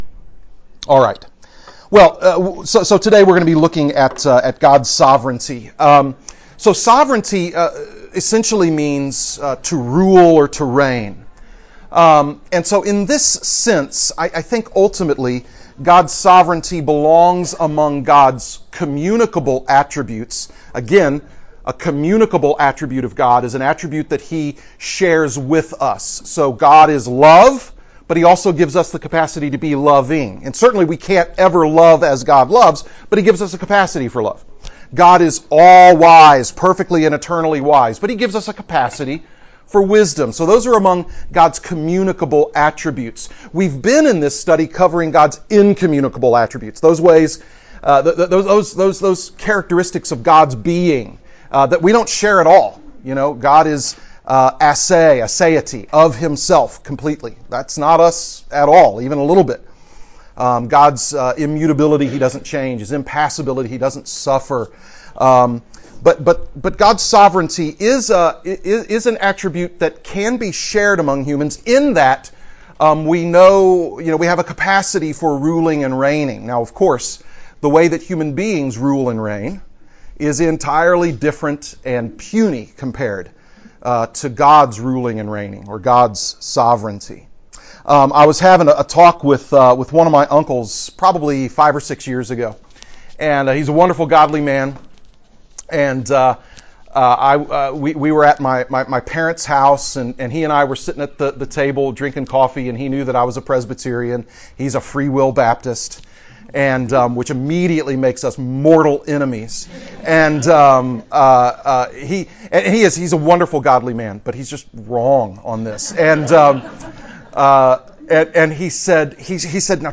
Portions of the audio have been edited during times of class interaction due to low sound quality.